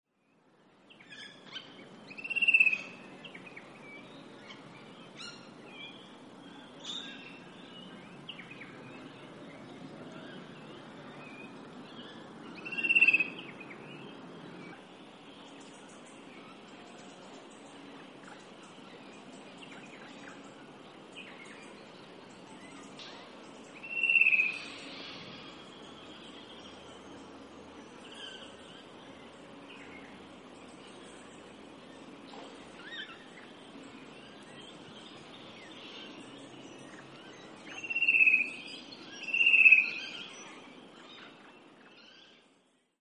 Fantailed Cuckoo - Cuculus pyrrhophanus
Voice: mournful descending trill, often repeated.
Call 1: three single calls, then a double call
Fantailed_Cuckoo.mp3